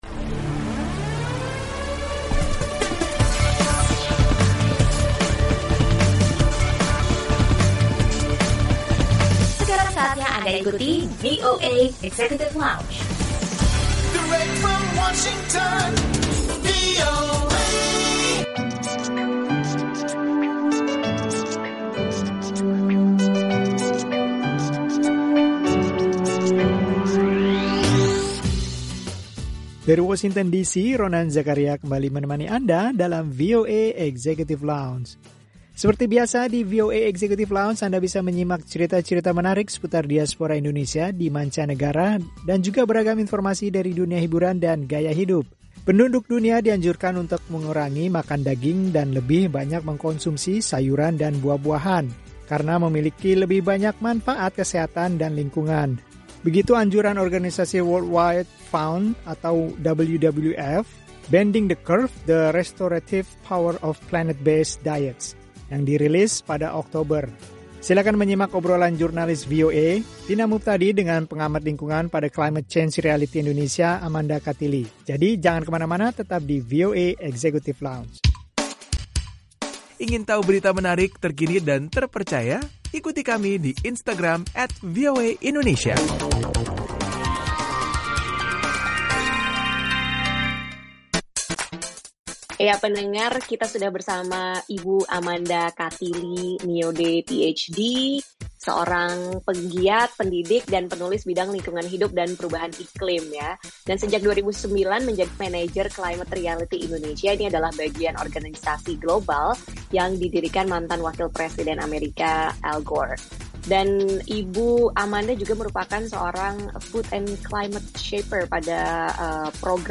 Bincang-bincang